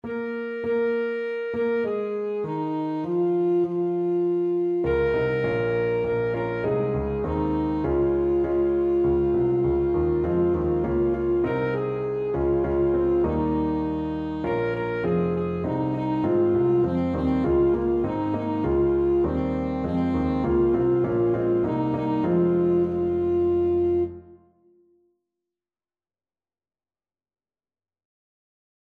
Alto Saxophone version
Alto Saxophone
4/4 (View more 4/4 Music)